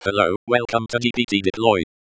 text_to_speech_example.wav